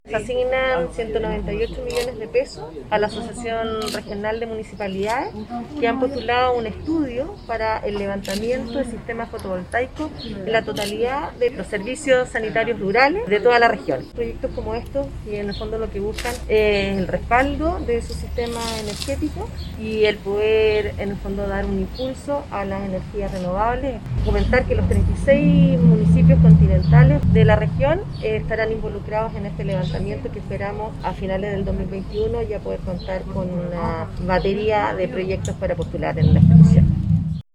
La presentación de la iniciativa se efectuó en la sede del Comité de Agua Potable Rural de La Palma, en Quillota y fue encabezada por el presidente de la Asociación Regional de Municipalidades y alcalde de Limache, Daniel Morales Espíndola.